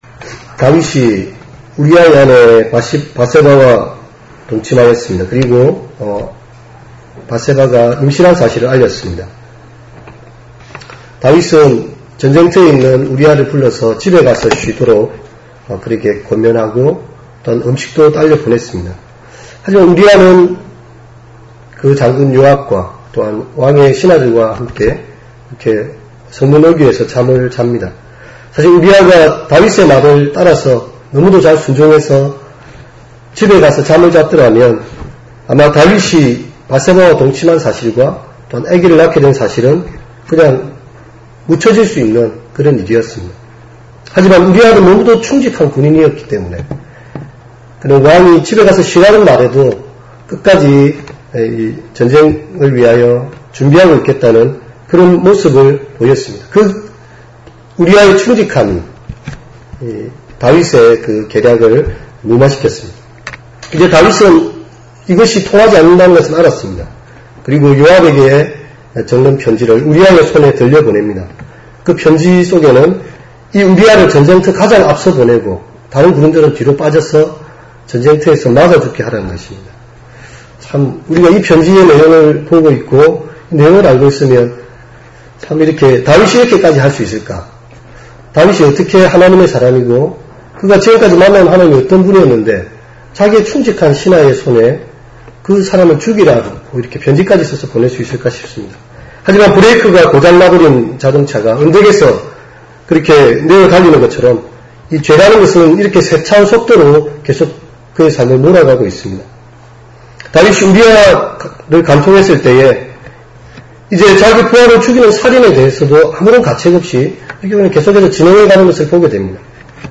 사무엘하 11장14-24절 말씀의 새벽설교를 음성화일로 남깁니다. 사람은 외모를 보시지만 하나님은 중심을 보십니다.